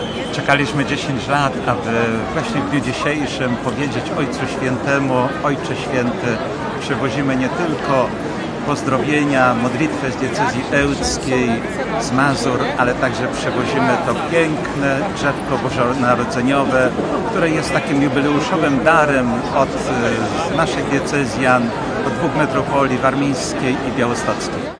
– mówił biskup ełcki Jerzy Mazur.